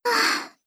sigh.wav